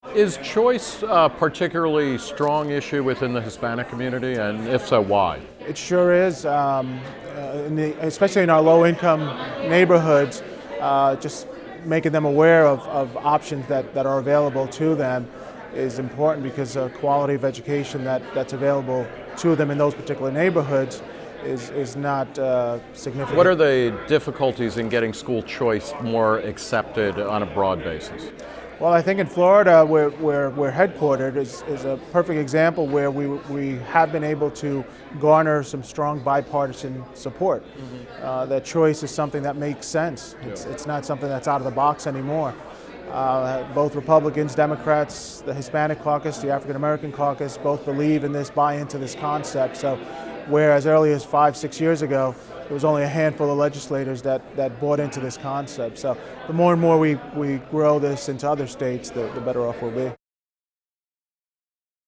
This interview is part of National School Choice Week, a non-partisan initiative to raise awareness of how competition and choice can transform K-12 education.